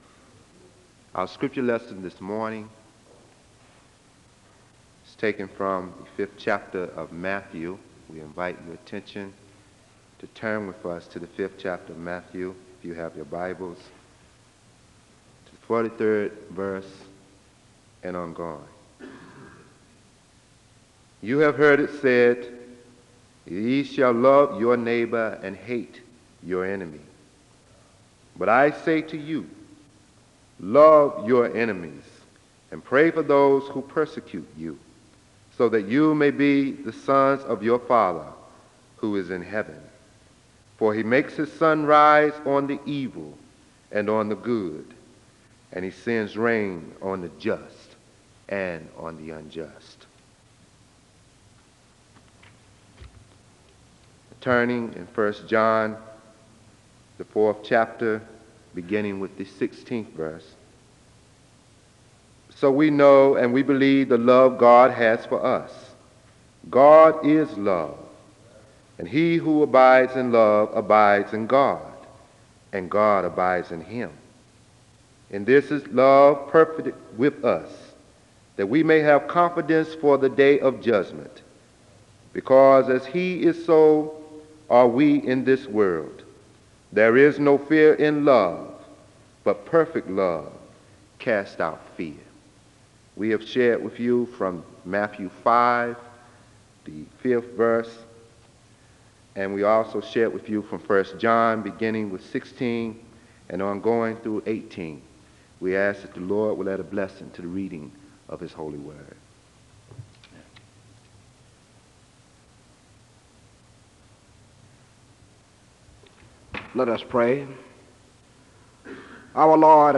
Chapel is opened in prayer (1:50-3:15). Choir leads in song (3:15-6:35).
He says that God is a God of history and still reigns over His universe which influences how we live and keeps us active in God’s work (16:00-24:25). A benediction is given to conclude the service (24:25-24:43).